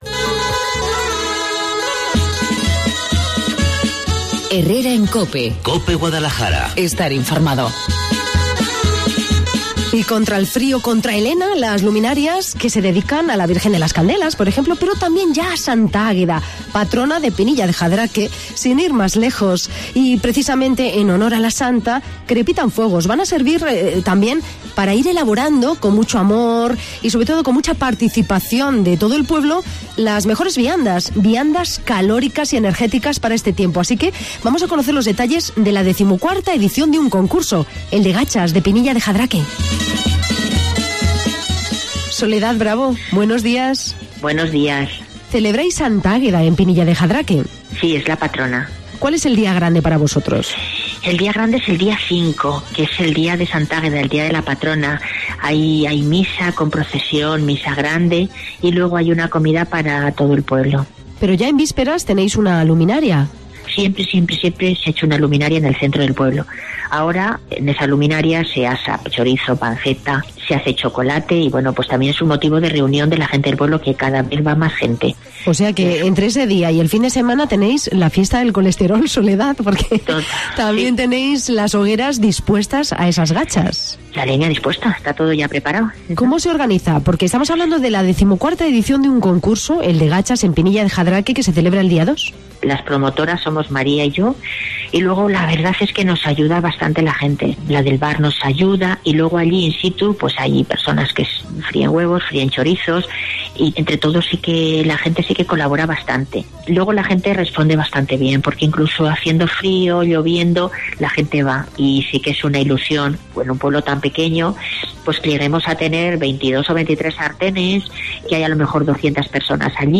En Cope Guadalajara, hablamos con una de sus organizadoras